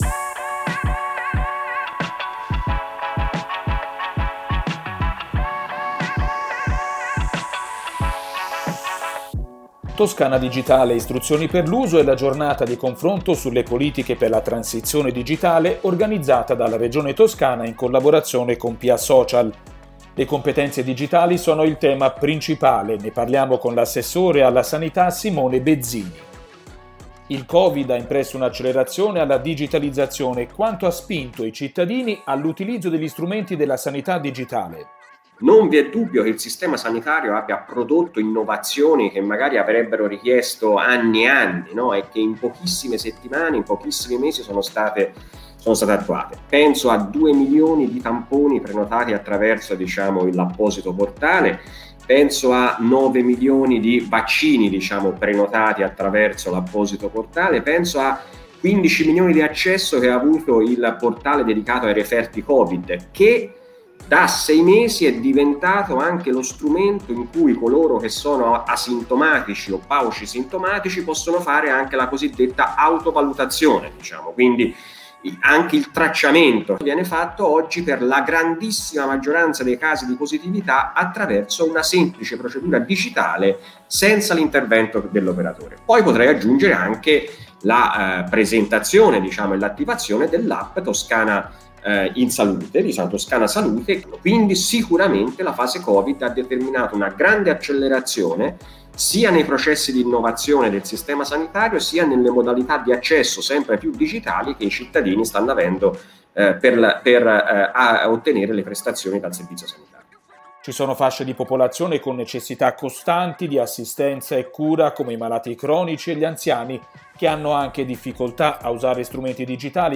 Lunedì 11 luglio al Teatro della Compagnia di Firenze
Ascolta l'intervento dell'Assessore alla Sanità, Simone Bezzini: